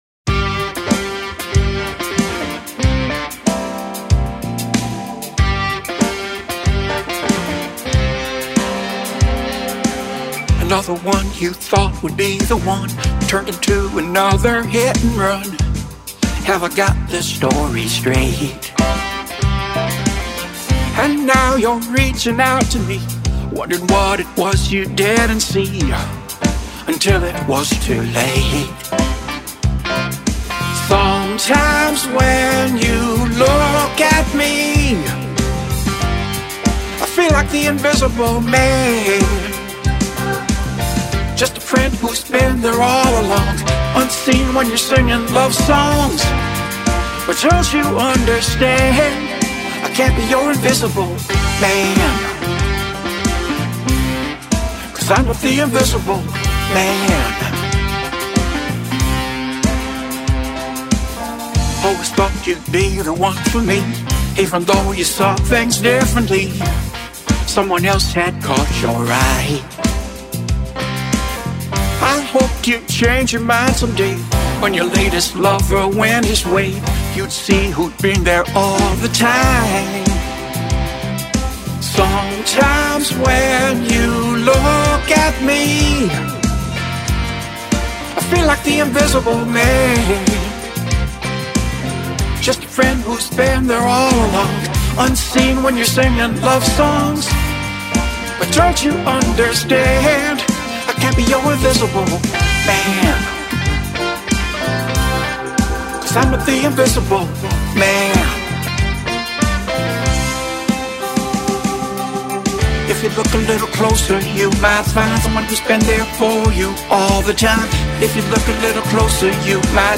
Pop-Rock Version